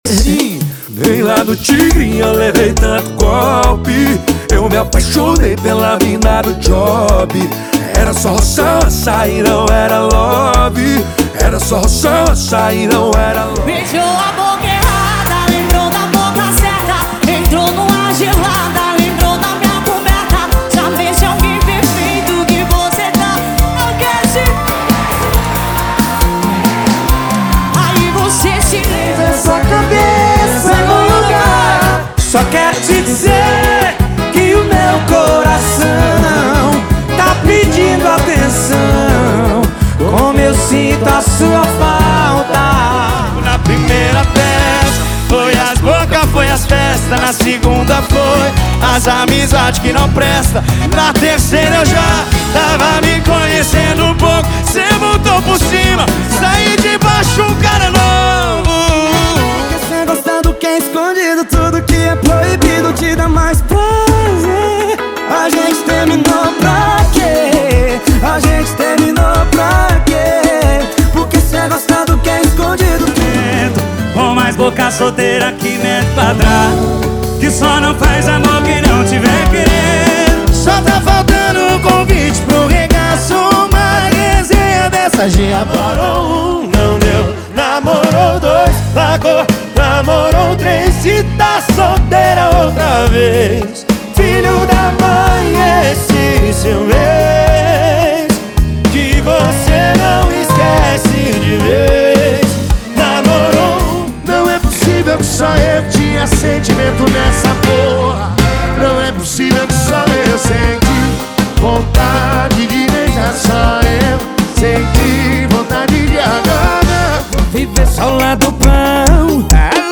• Sertanejo = 157 Músicas
• Sem Vinhetas